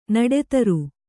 ♪ naḍe taru